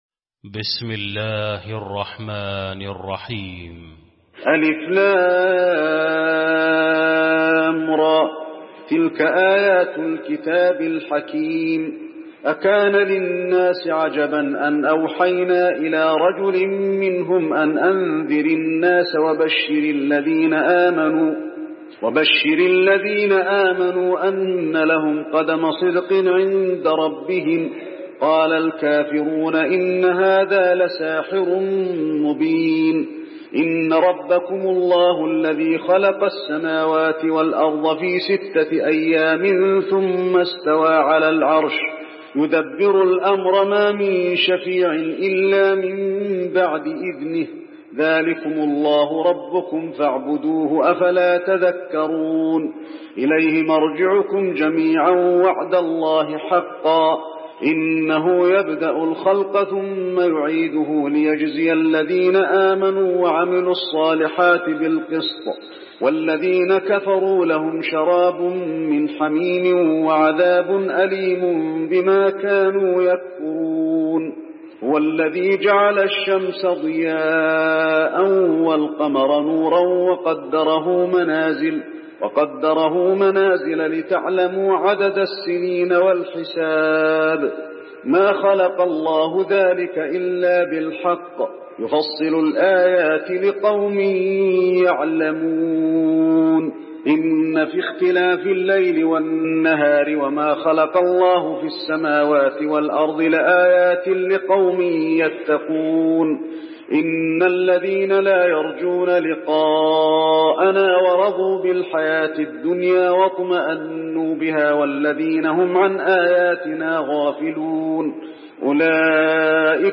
المكان: المسجد النبوي يونس